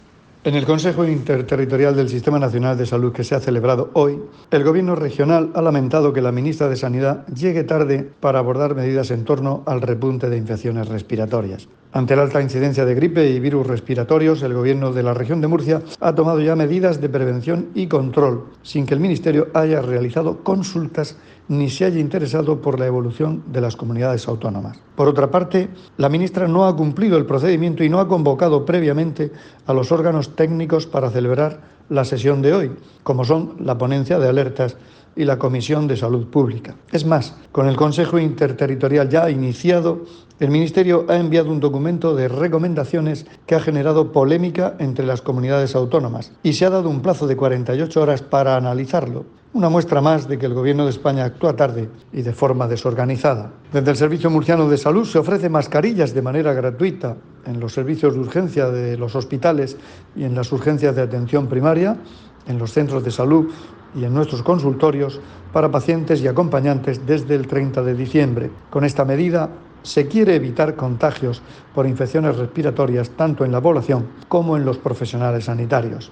SONIDO/ Declaraciones del consejero de Salud, Juan José Pedreño, sobre la reunión del Consejo Interterritorial del Sistema de Salud celebrada hoy.